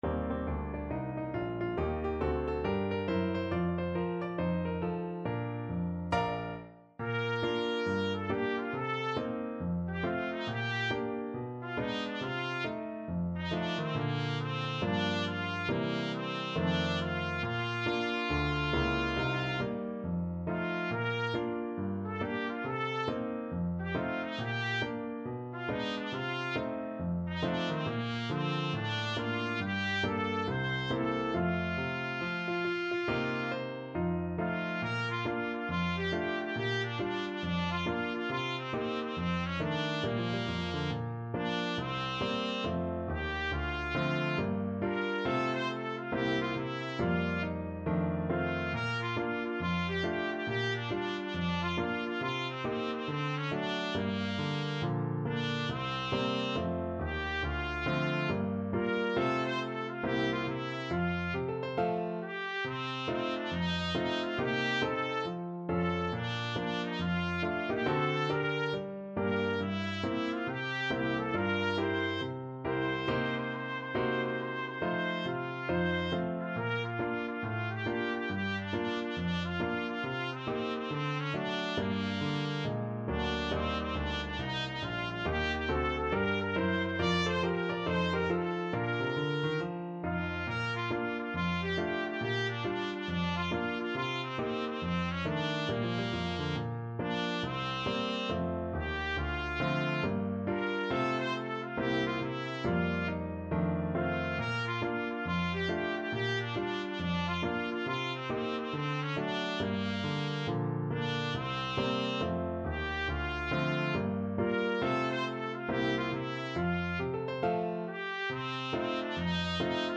With a swing =c.69
4/4 (View more 4/4 Music)
Pop (View more Pop Trumpet Music)